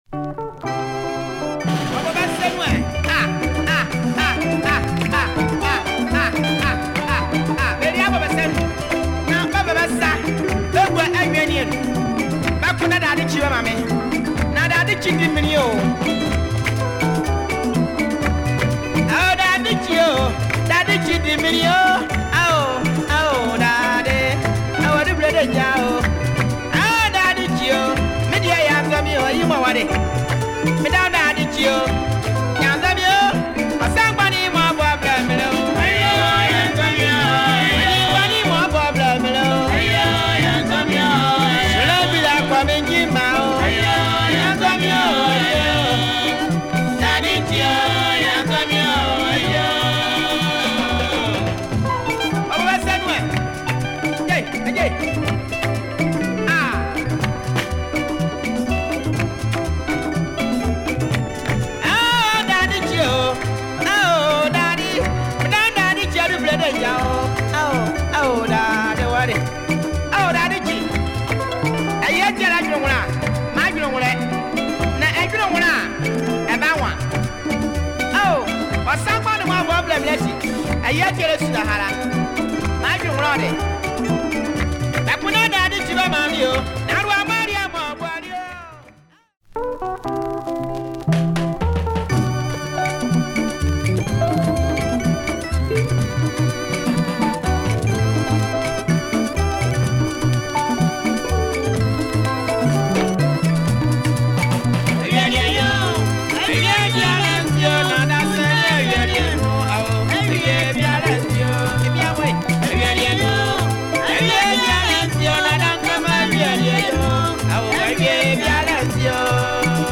soundalike album from Ghana